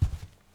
krok_03.wav